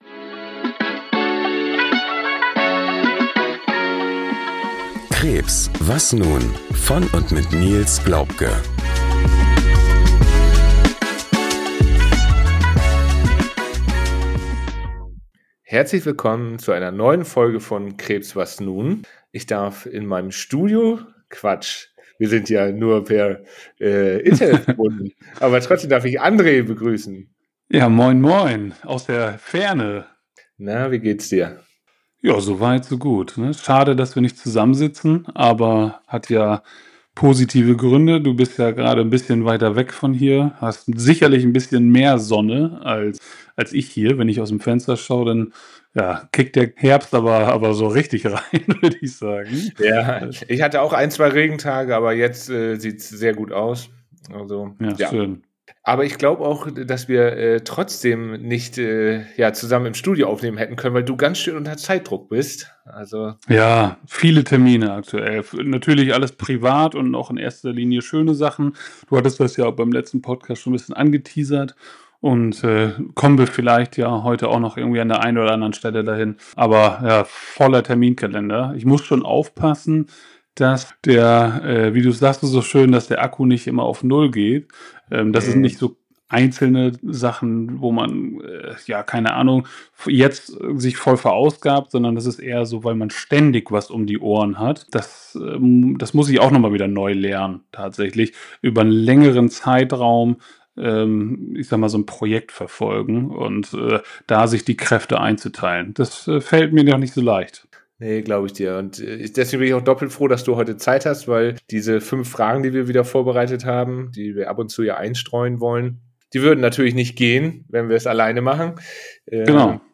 Ein Gespräch über Nähe, Mut und den Wert eines Augenblicks.